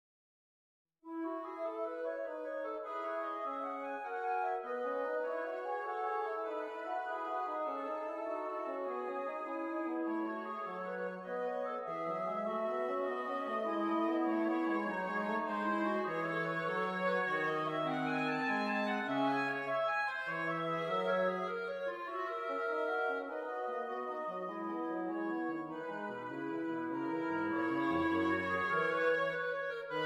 Woodwind Trio: Oboe, Clarinet in Bb, Bassoon
Does Not Contain Lyrics
E Major
Allegro moderato